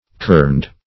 Kerned \Kerned\ (k[~e]rnd), a. (Print.)